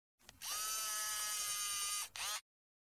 Зум для видеомонтажа